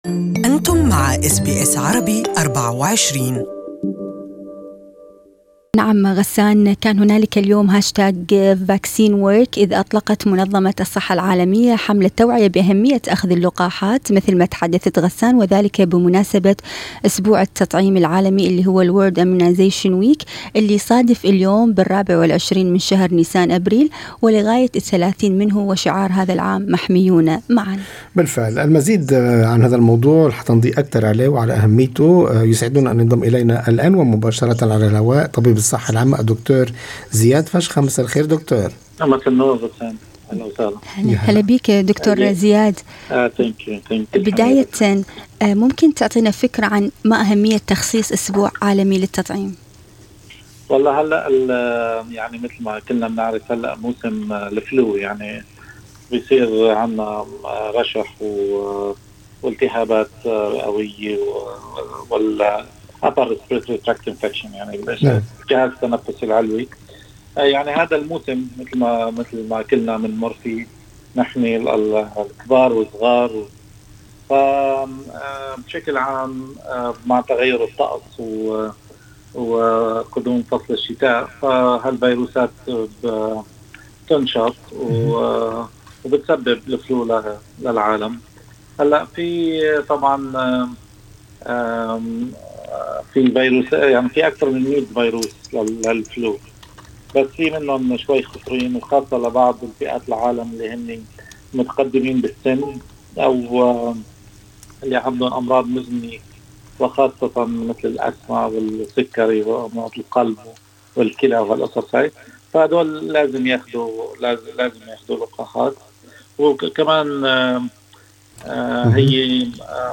المزيد في لقاءِ مباشر مع طبيب الصحة العامة